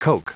Prononciation du mot coke en anglais (fichier audio)
Prononciation du mot : coke